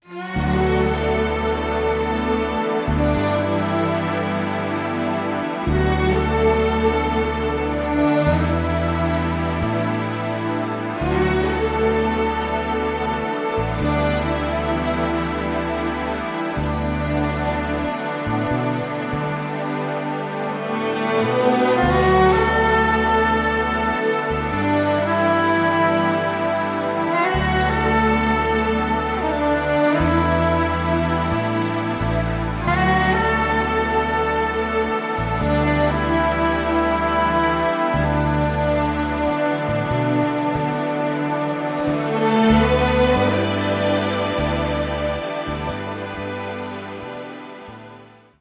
Spannung, Romantik und große Melodien.